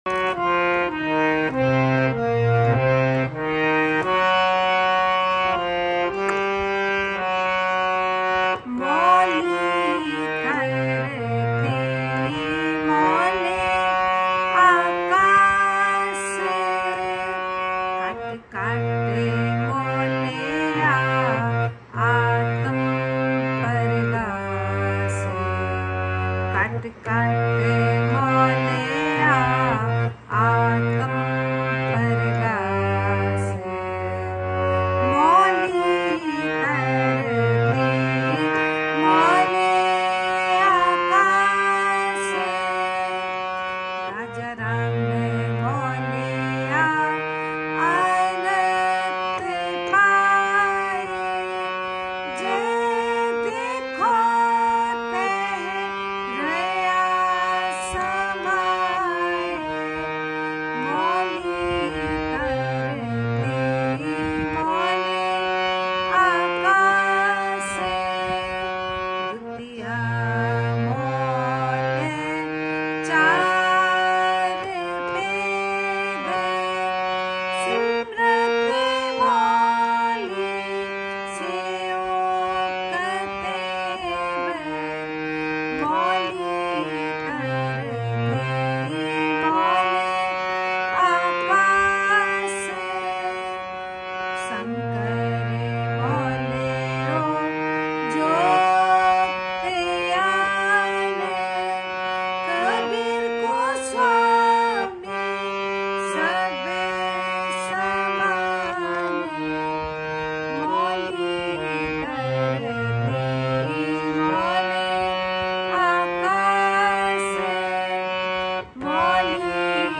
MGzhaGSFQyS_Learn-Shabad-MOLI-DHARTI-learning-gurbani-shabad-gurbanishabad-music.mp3